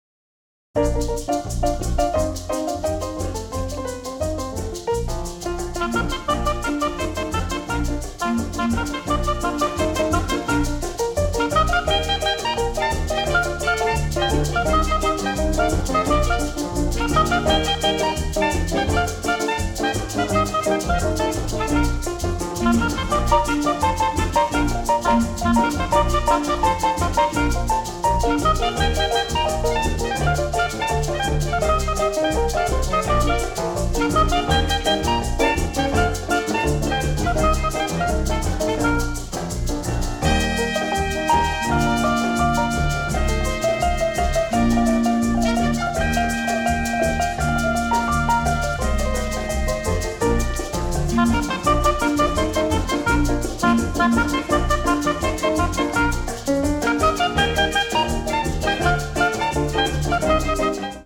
composed for jazz ensembles